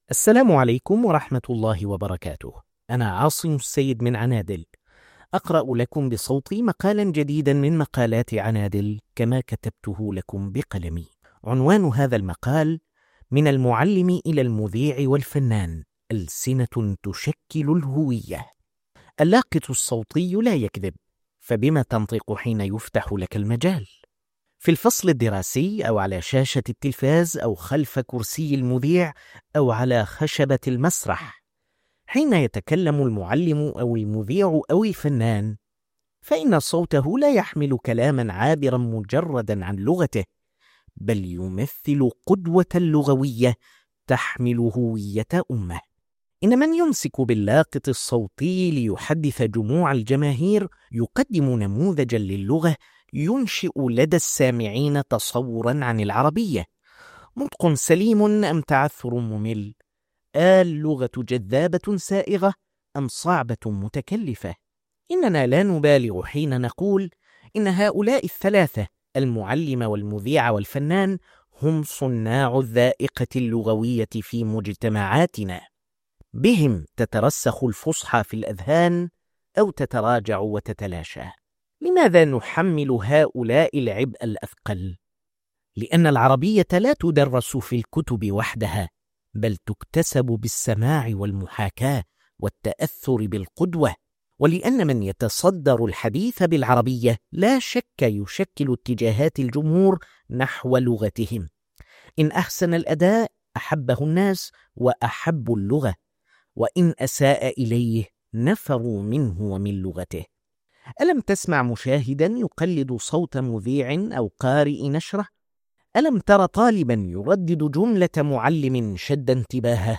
استمع إلى المقال بصوت الكاتب